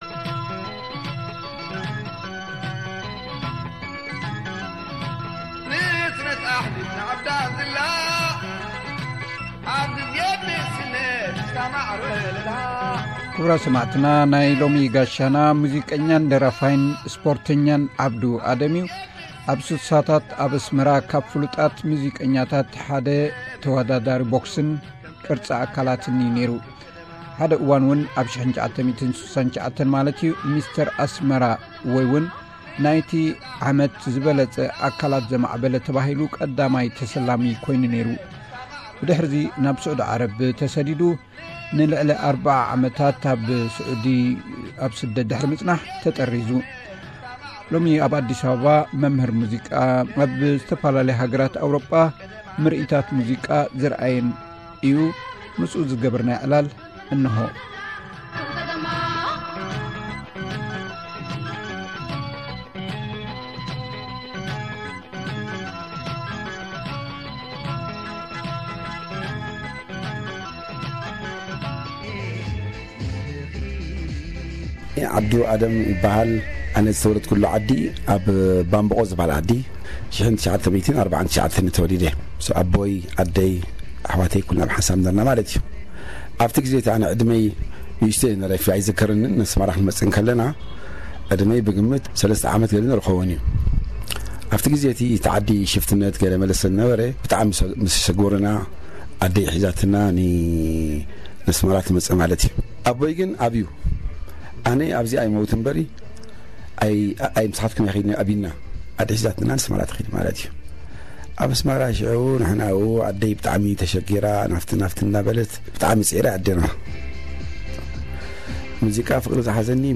ዕላል